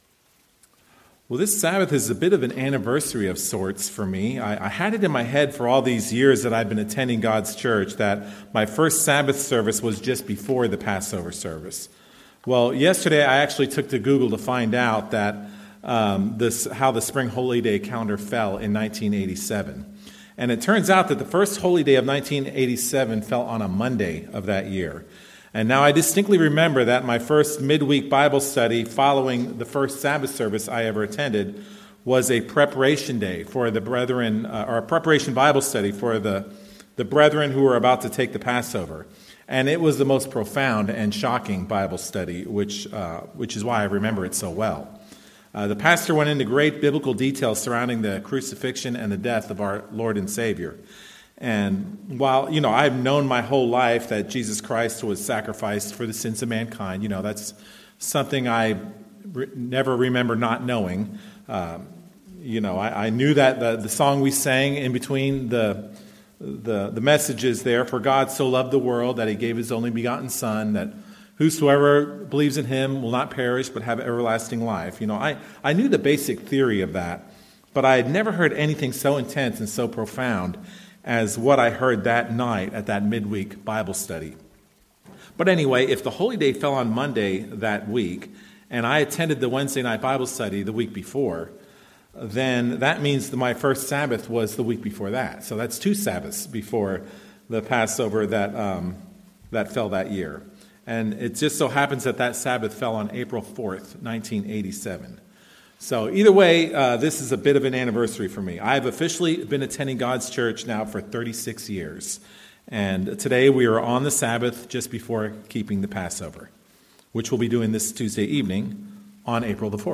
Given in Lawton, OK